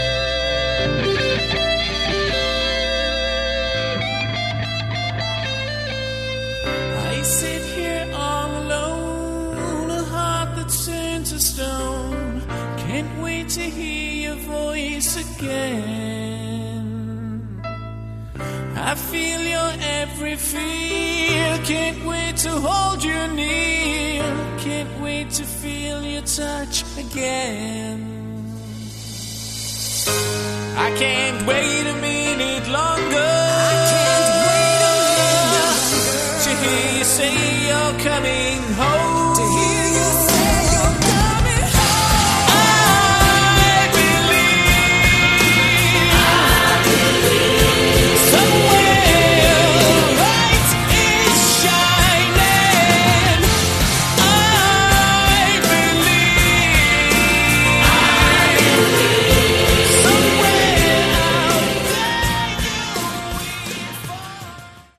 Category: Glam
Vocals
Bass
Guitar
Drums
Keyboards